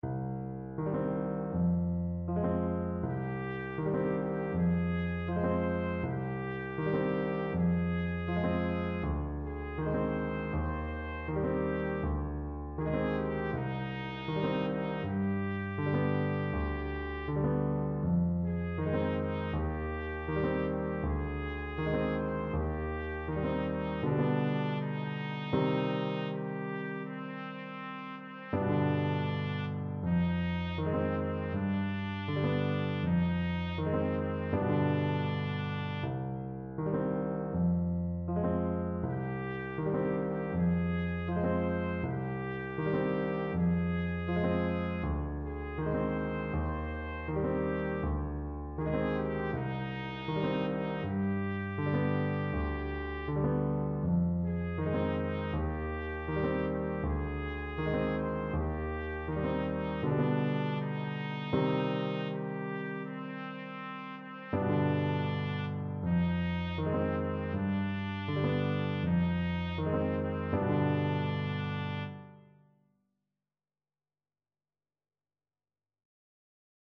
Trumpet
Traditional Music of unknown author.
C minor (Sounding Pitch) D minor (Trumpet in Bb) (View more C minor Music for Trumpet )
4/4 (View more 4/4 Music)
Andante
C5-C6